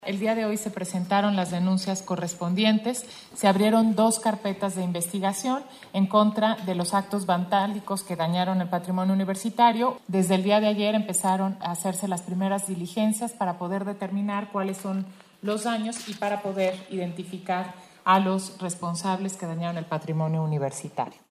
En conferencia de medios, Leonardo Lomelí Vanegas, secretario general, afirmó que atentar contra la Universidad Nacional es atentar contra el desarrollo de México.